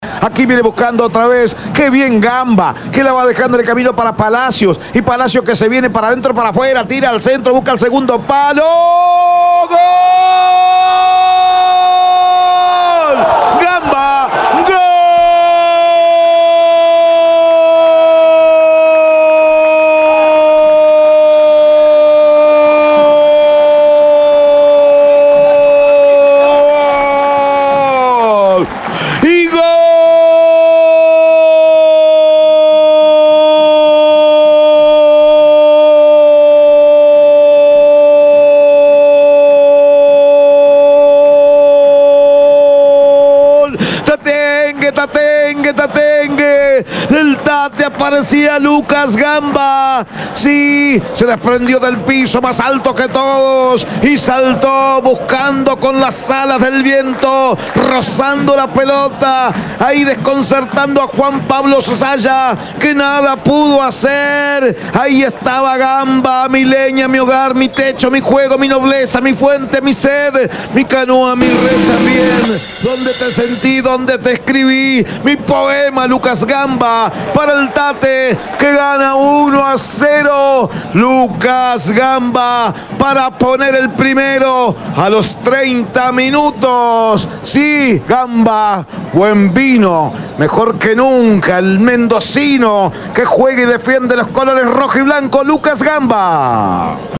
GOL-1-UNION-1.mp3